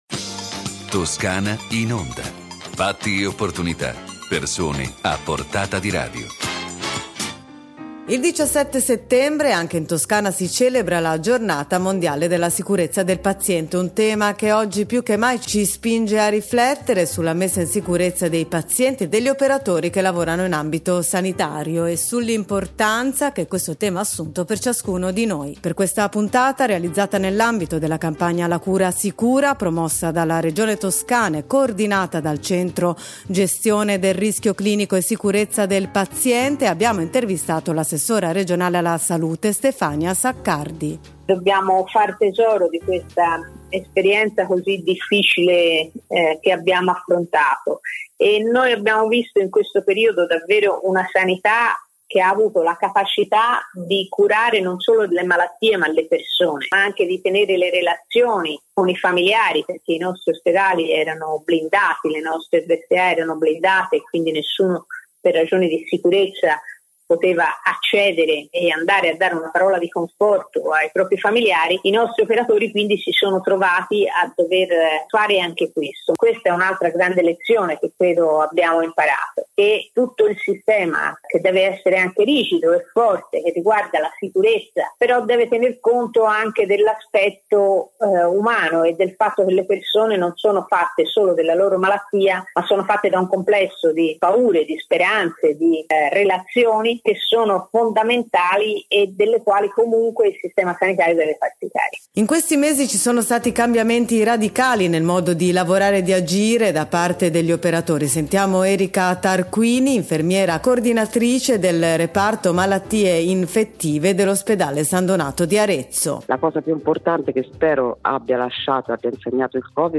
Le interviste radiofoniche